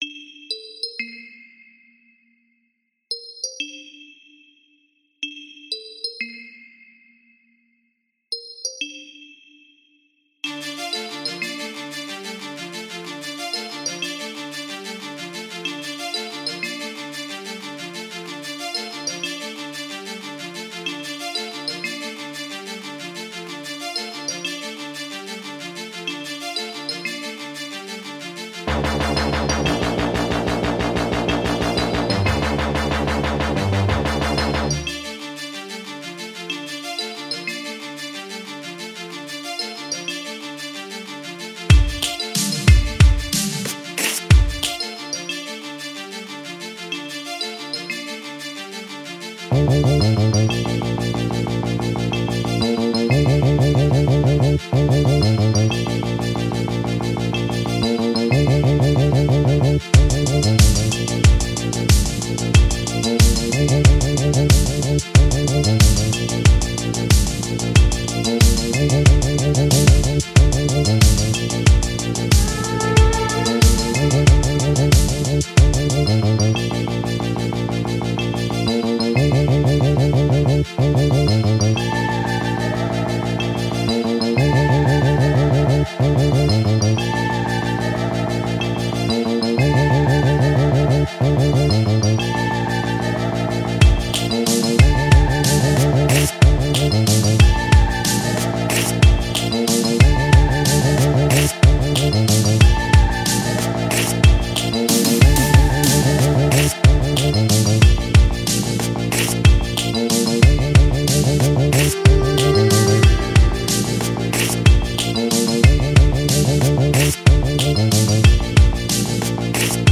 03:41 Genre : Hip Hop Size